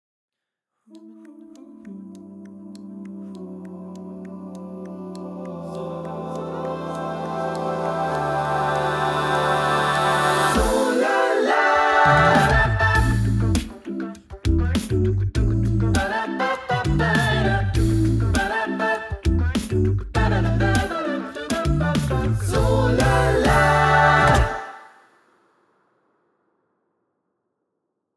Wir finden es saucool und groovy!